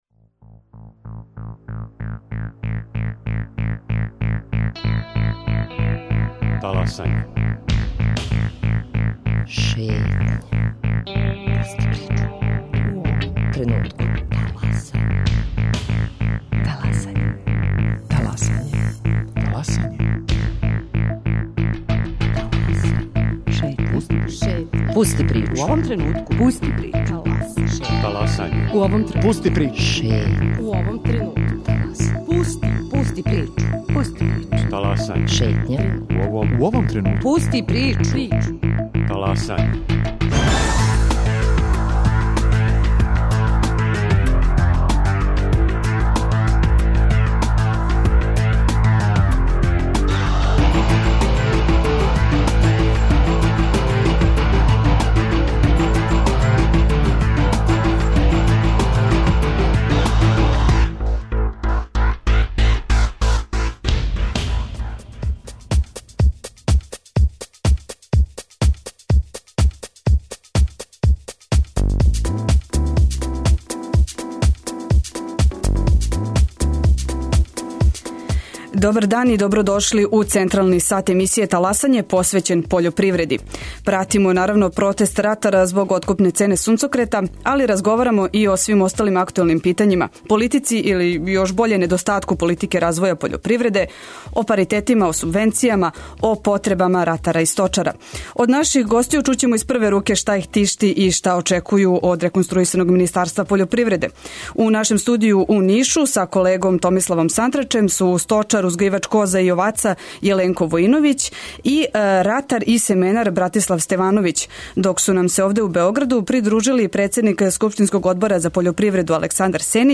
Шта од државе очекују произвођачи, које мере препоручује струка и како могу да помогну политичари - о томе директно и без посредника говоре ратари и сточари са југа Србије, у нашем студију у Нишу.